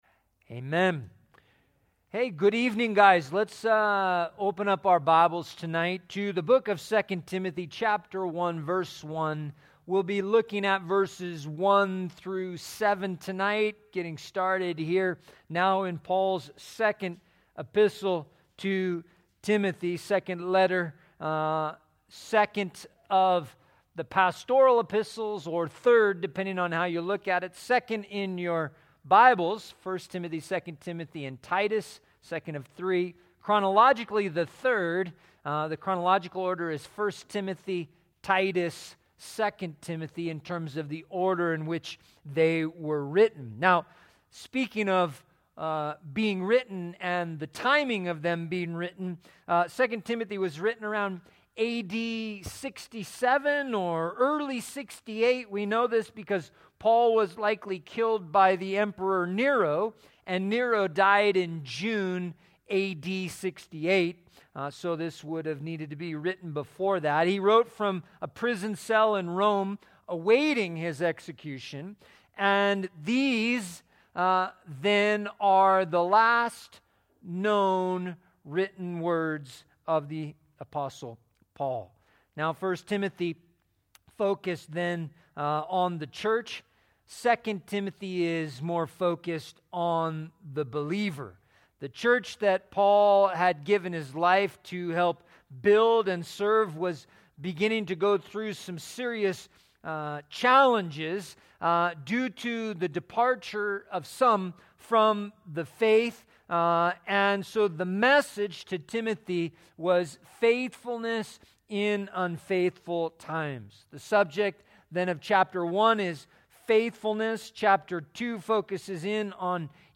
A verse by verse study through the Bible